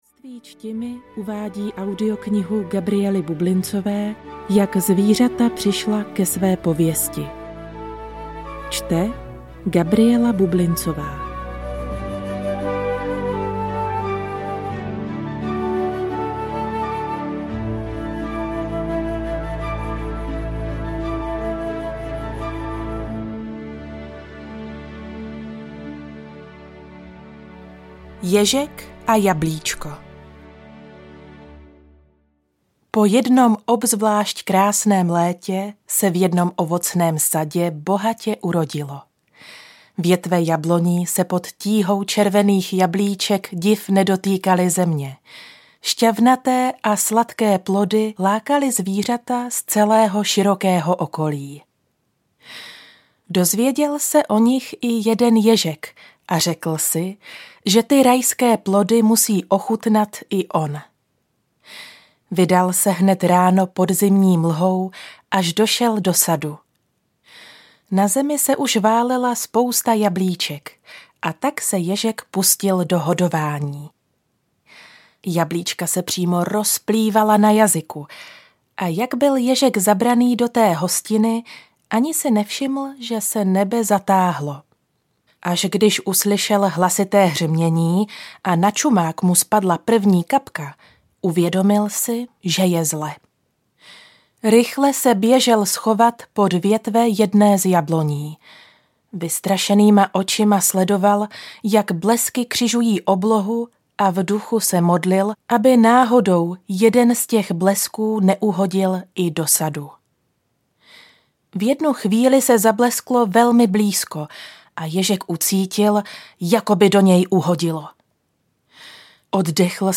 Ukázka z knihy
jak-zvirata-prisla-ke-sve-povesti-audiokniha